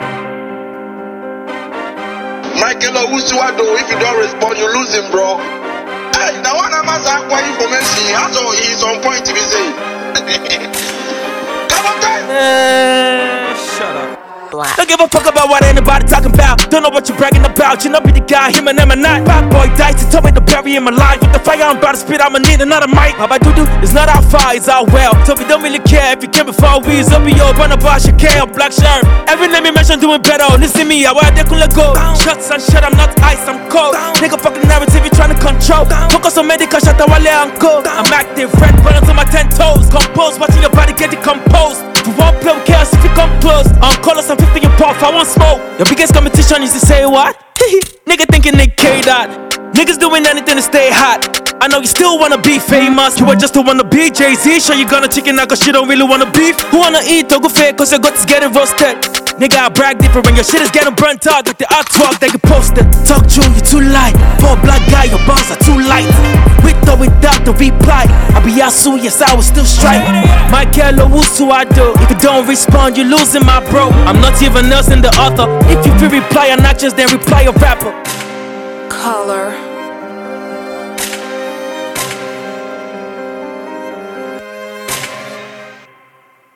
a highly gifted Nigerian rapper
caustic diss track